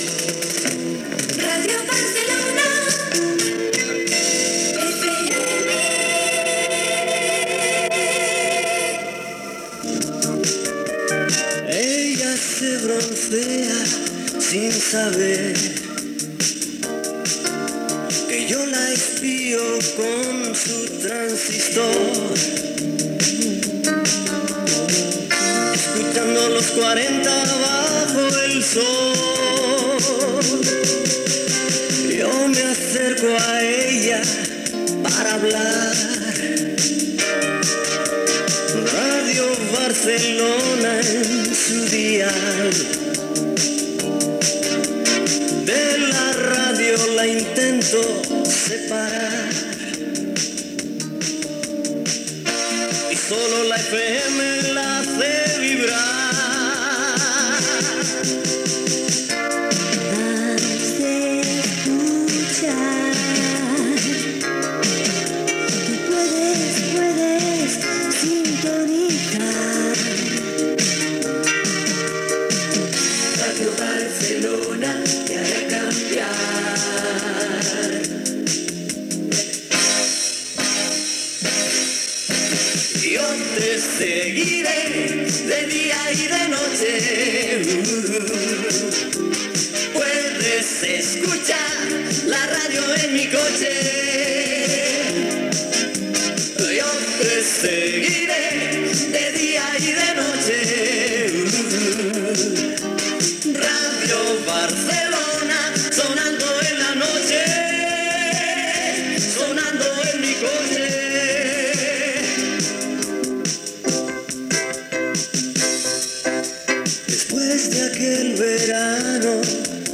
fe4b3bd0847bd9faba28f4daf7d6bd8b67482a5b.mp3 Títol Ràdio Barcelona FM Emissora Ràdio Barcelona FM Cadena SER Titularitat Privada estatal Descripció Indicatiu de l'emissora i cançó de Los 40 Principales a Ràdio Barcelona FM. Gènere radiofònic Musical Data emissió 198?